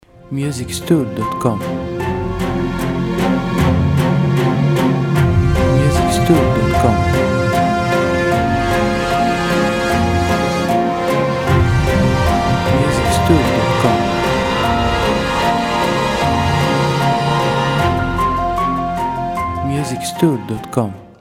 • Type : Instrumental / Audio Track
• Bpm : Moderato
• Genre : Action / Battle Soundtrack